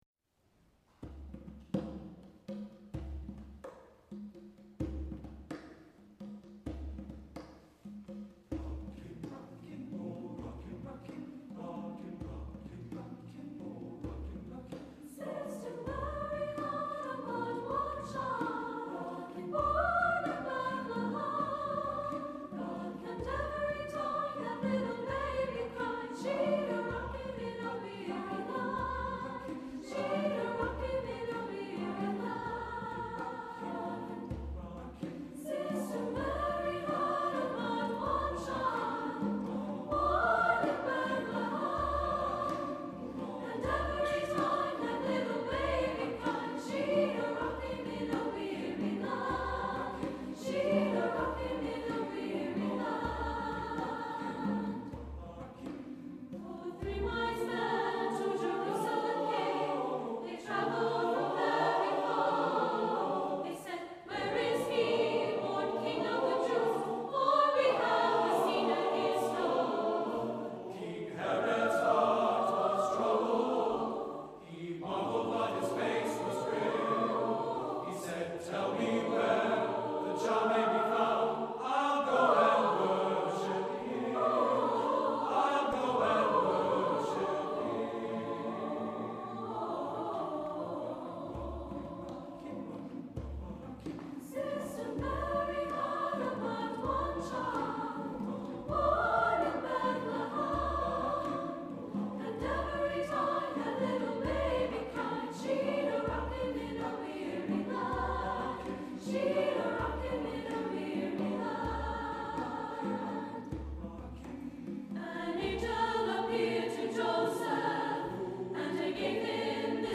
Voicing: SSAATTBB